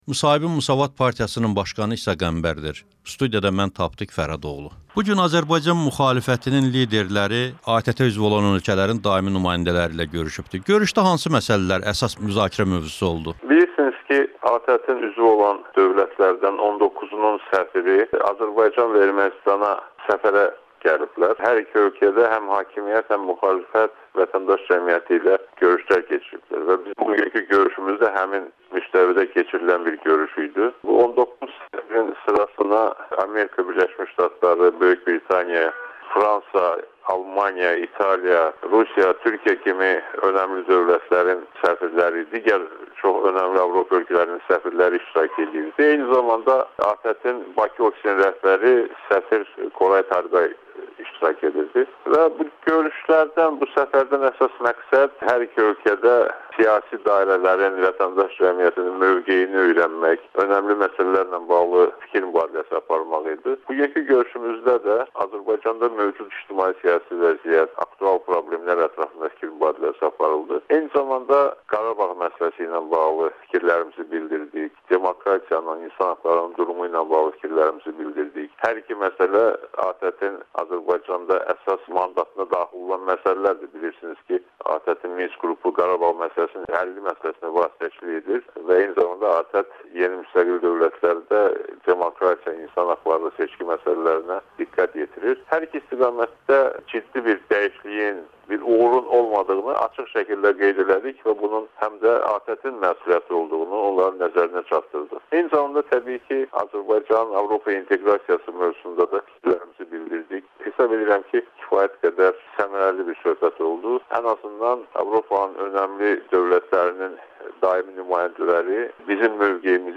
İsa Qəmbərlə müsahibə